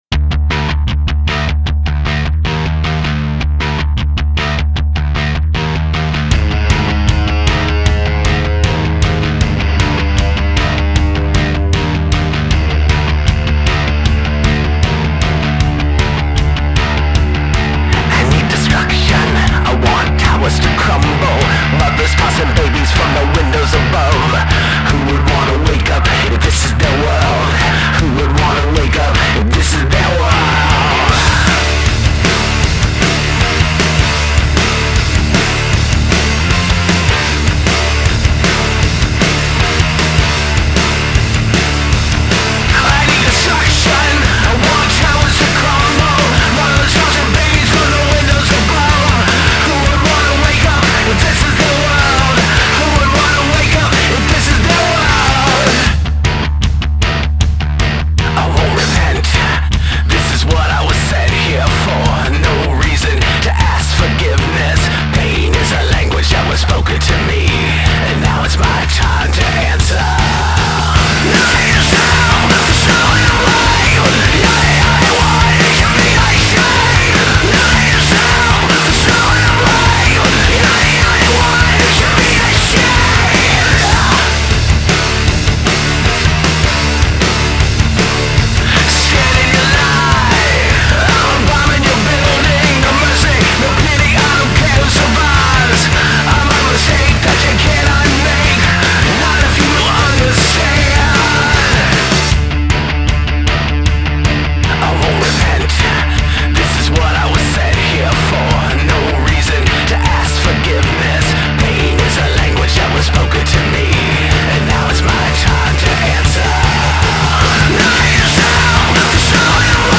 • Жанр: Metal